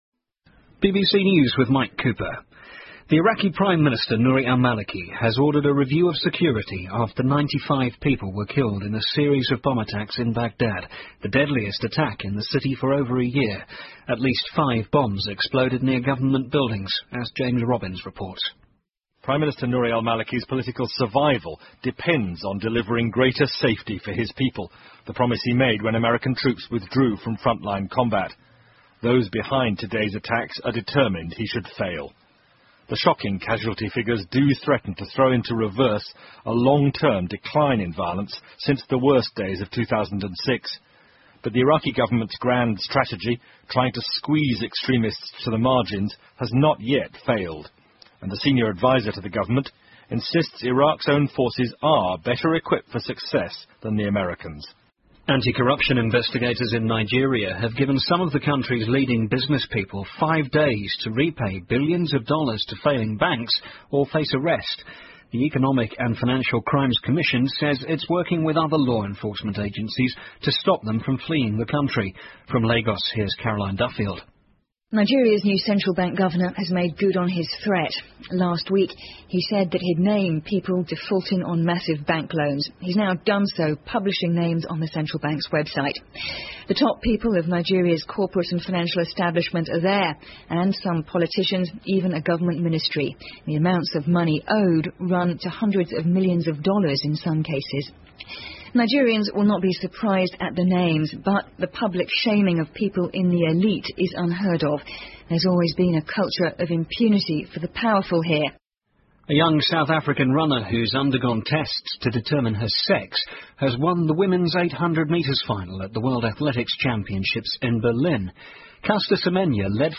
英国新闻听力 美国黑客窃取1.3亿张信用卡信息遭到起诉 听力文件下载—在线英语听力室